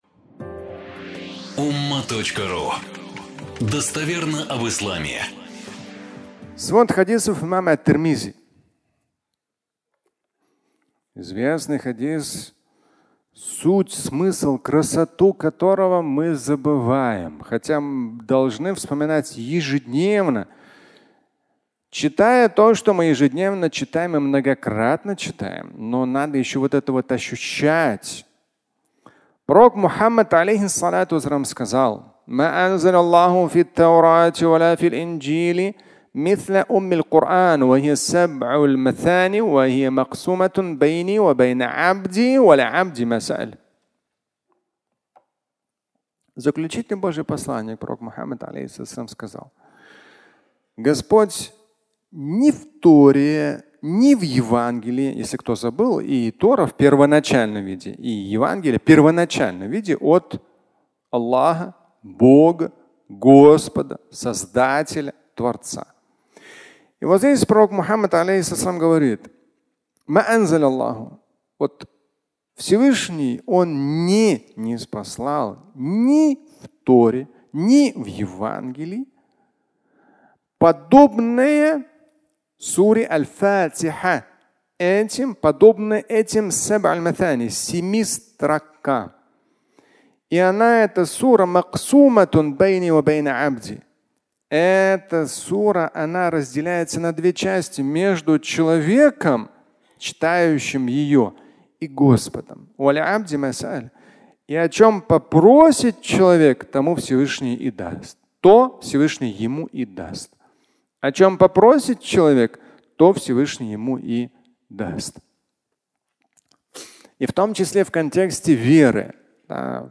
Тора и Евангелия (аудиолекция)